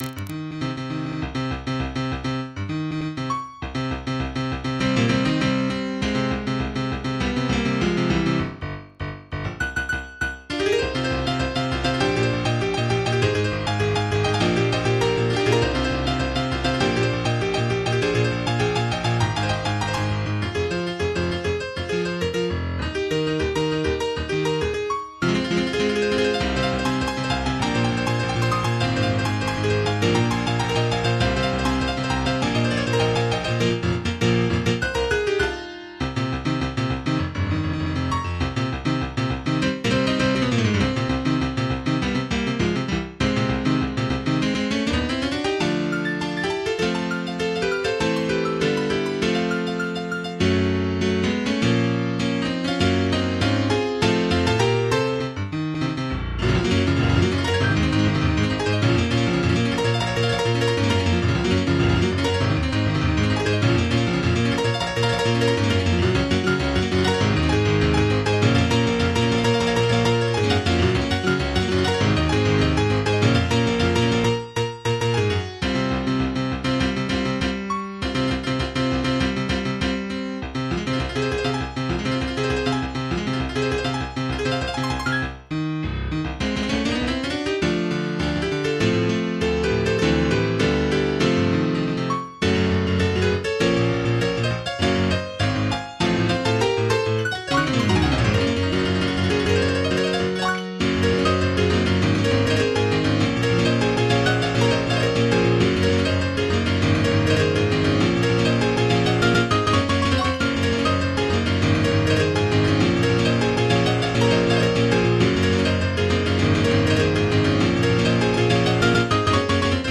Emotional Hardcore Techno